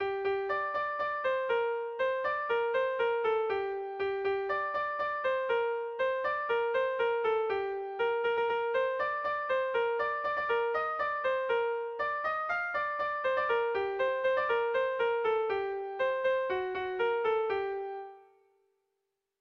Bertso melodies - View details   To know more about this section
Irrizkoa
ABDE